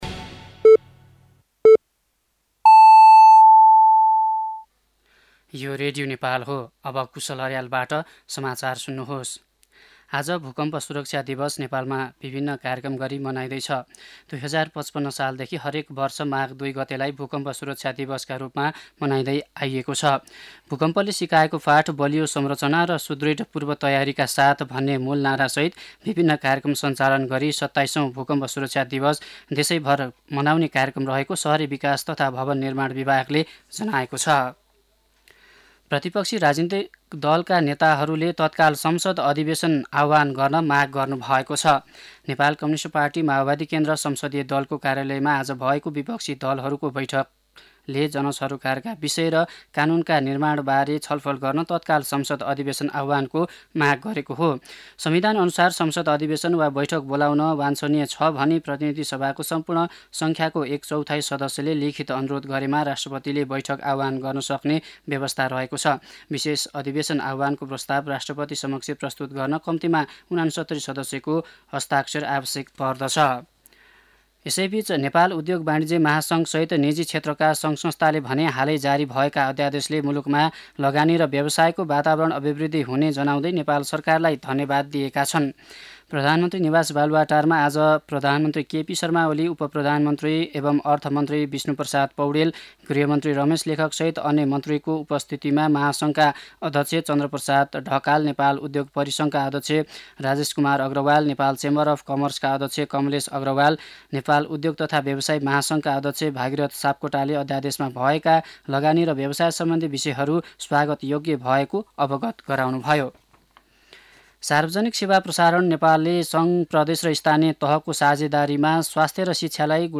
दिउँसो ४ बजेको नेपाली समाचार : ३ माघ , २०८१
4-PM-Nepali-News-10-2.mp3